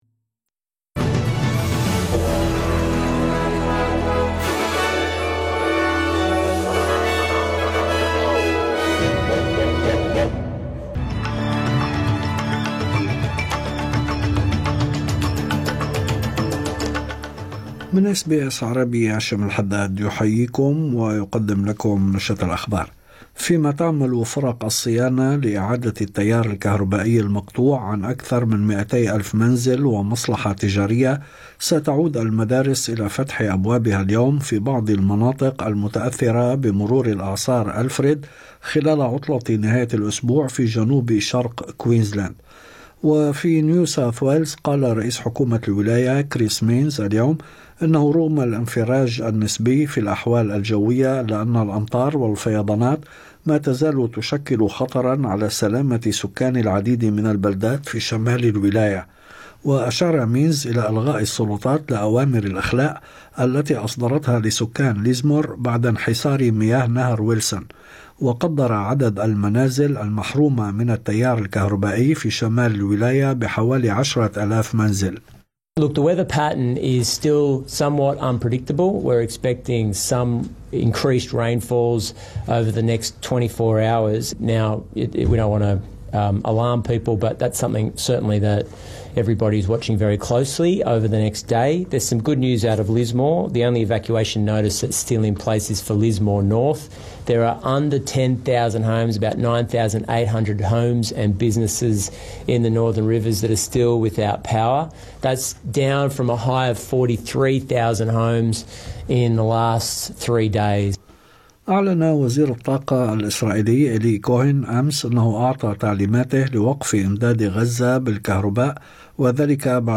نشرة أخبار الظهيرة 10/3/2025